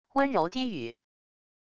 温柔低语wav音频